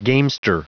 Prononciation du mot gamester en anglais (fichier audio)
Prononciation du mot : gamester